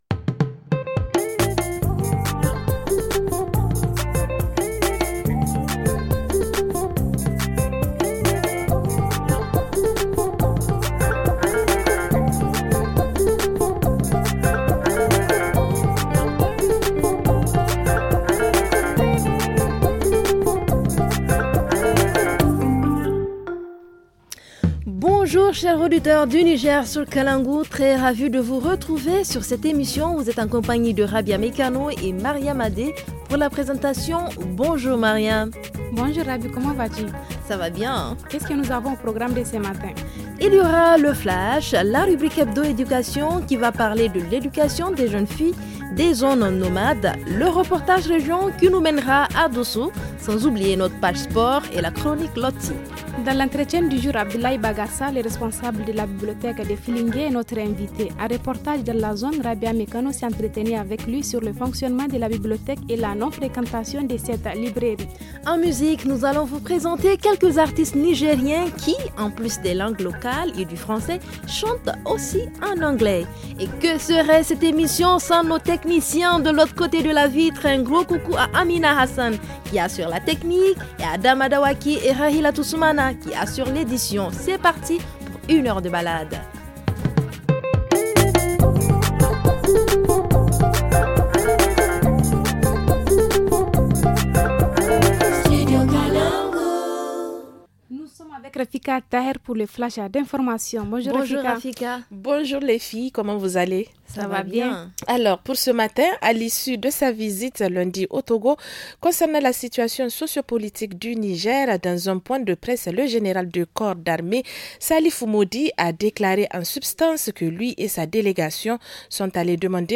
– Entretien